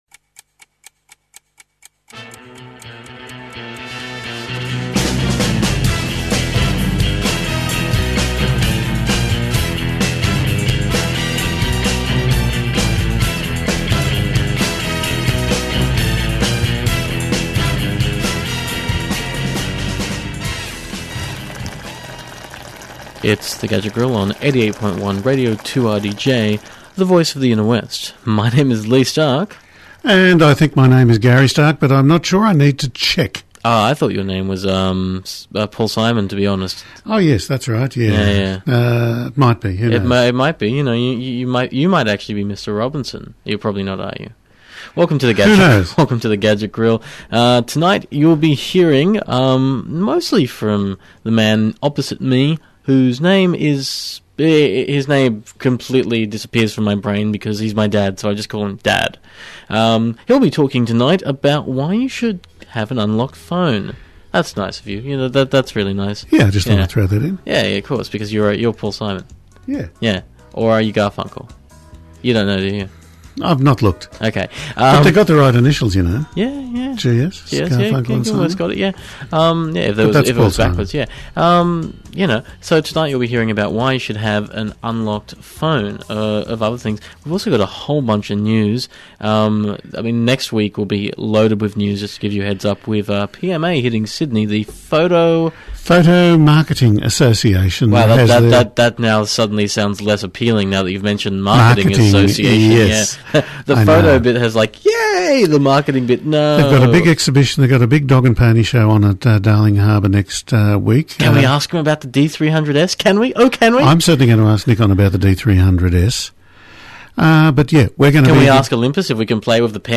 Podcast: Play in new window | Download (14.7MB) Subscribe: RSS Every week, we try to play a variety of music.